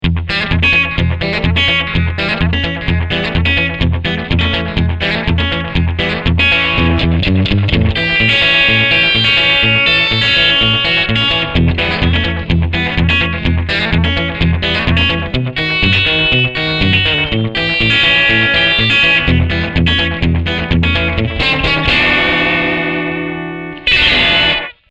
AMPRockabillySlap.mp3